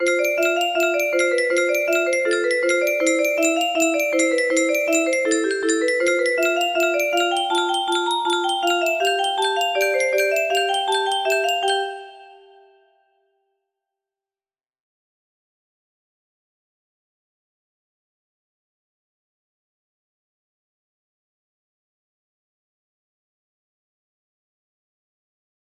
the void within my heart music box melody